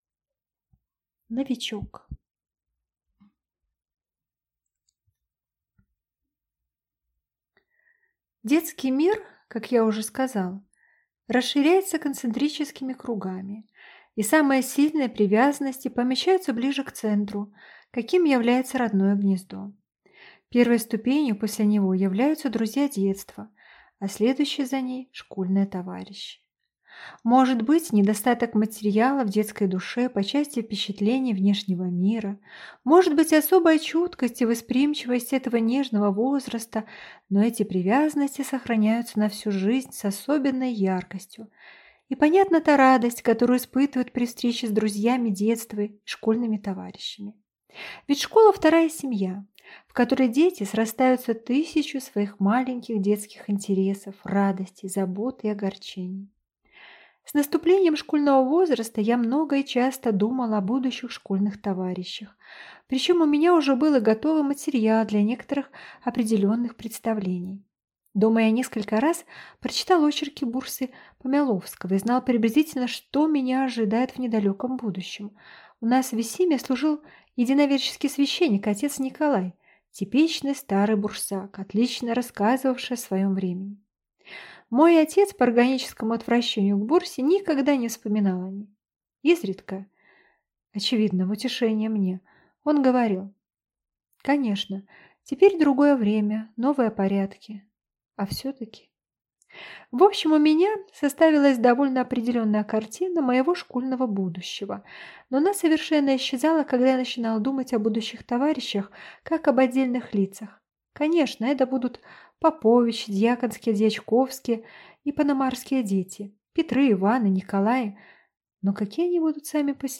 Аудиокнига Новичок | Библиотека аудиокниг